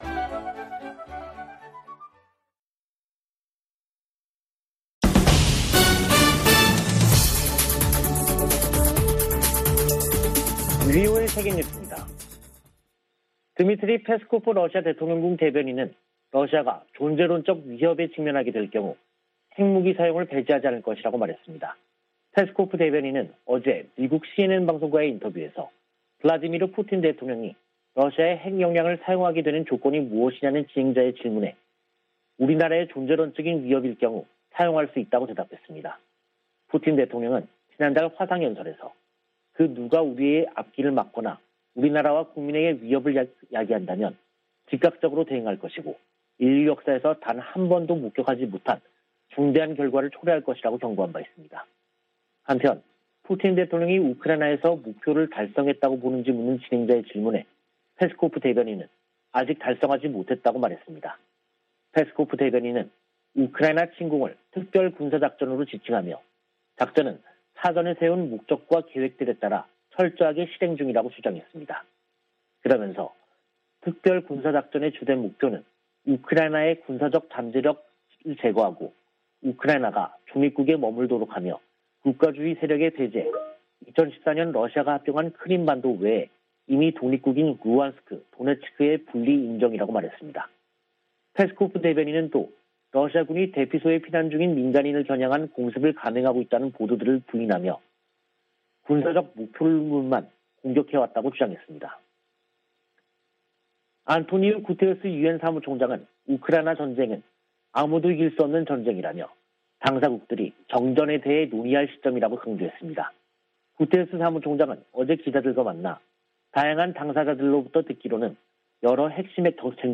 VOA 한국어 간판 뉴스 프로그램 '뉴스 투데이', 2022년 3월 23일 2부 방송입니다. 북한 해킹 조직이 러시아 등 사이버 범죄자들과 협력하고 있다고 백악관 국가안보보좌관이 지적했습니다. 독자 대북제재를 강화하고 있는 조 바이든 미국 행정부는 지난 3개월간 20건이 넘는 제재를 가했습니다. 유엔 인권기구가 49차 유엔 인권이사회 보고에서 회원국들에 국내 탈북민들과 접촉하고 인권 유린 책임을 규명할 수 있게 보장해 줄 것을 촉구했습니다.